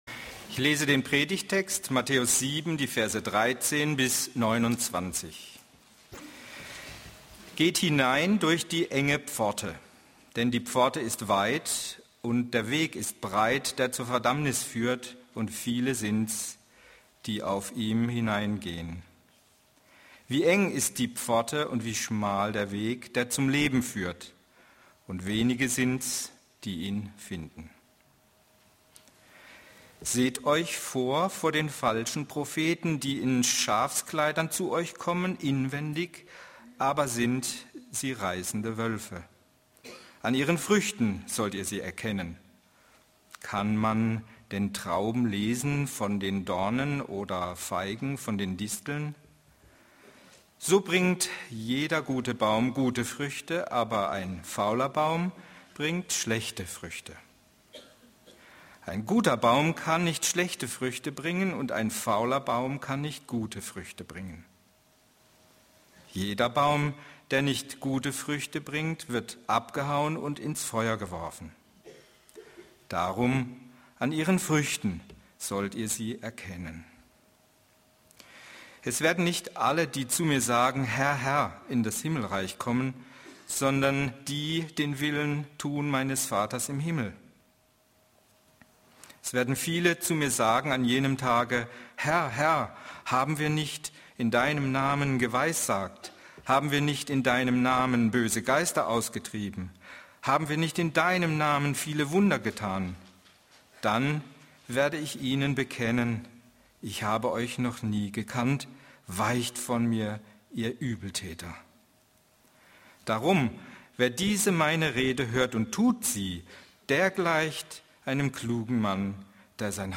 Andere Vorträge Vom Himmel Das Zuhause besser kennen